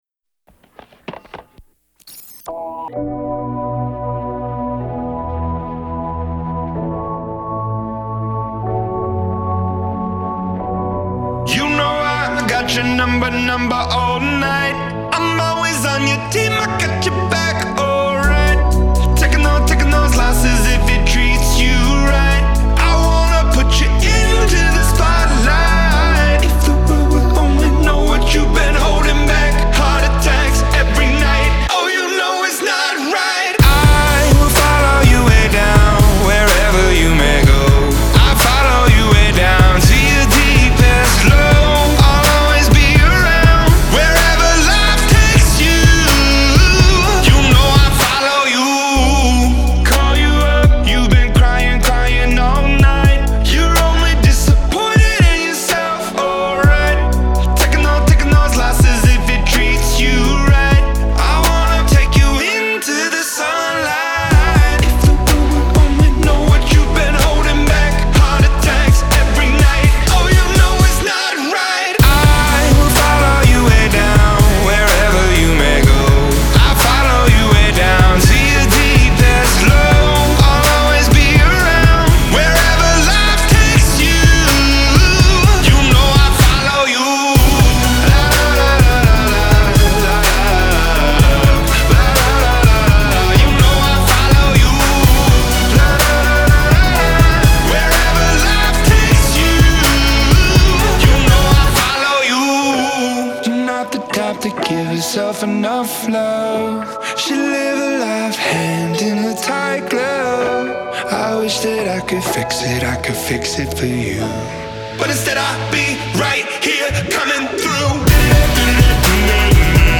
Genre : Alternative, Rock